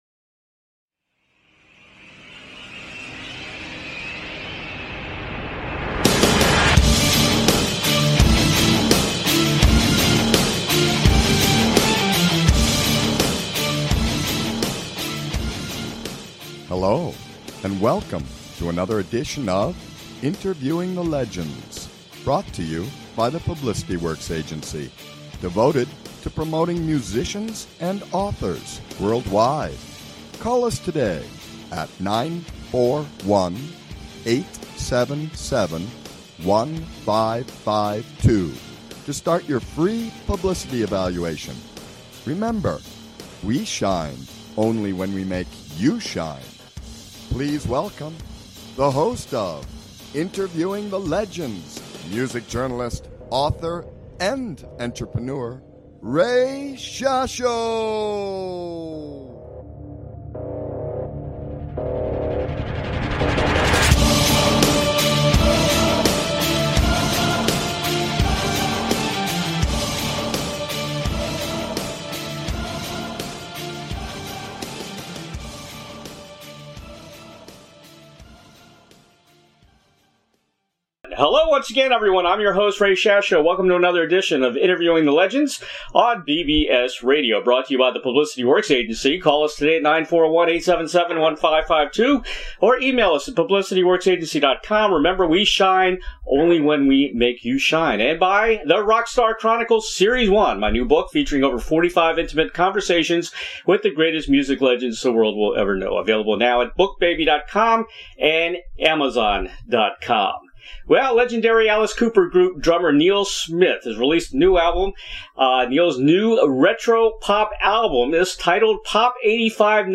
Neal Smith original drummer with the Alice Cooper Group special guest on Interviewing the Legends